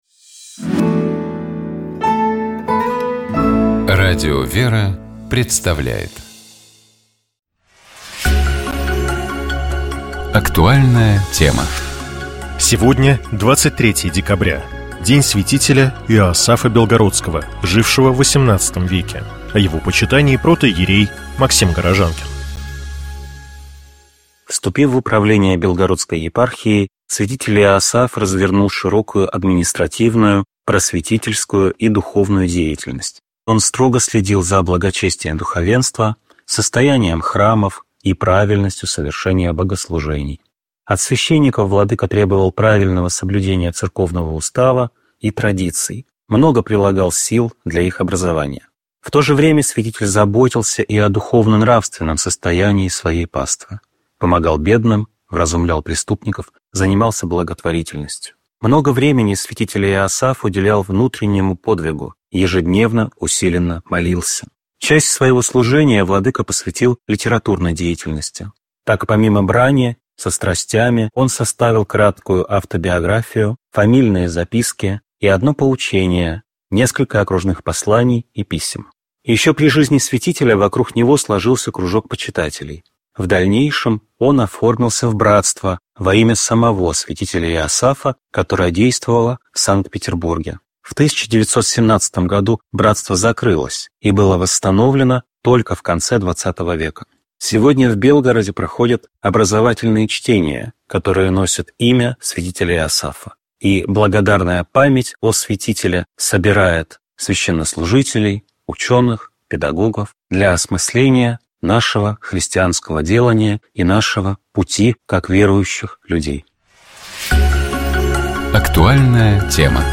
В нашей студии был клирик